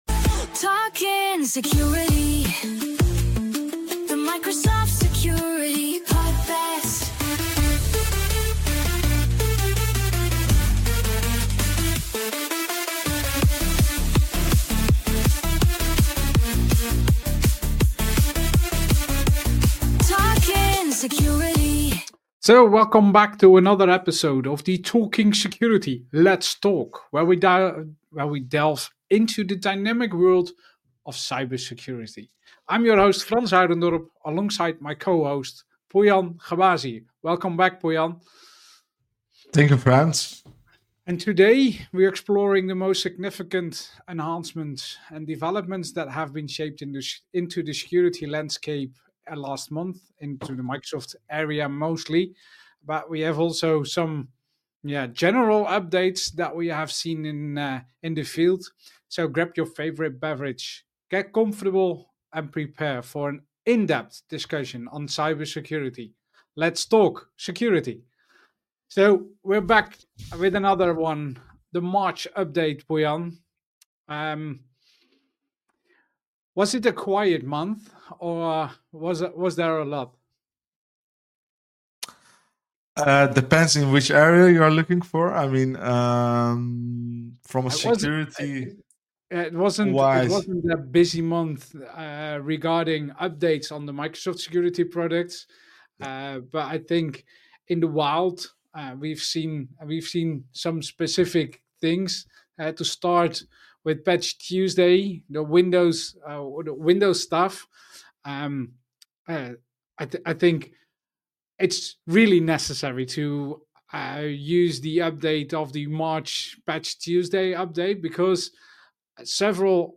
will talk with guests about Security related items. Most of the topics will be related to Microsoft Security.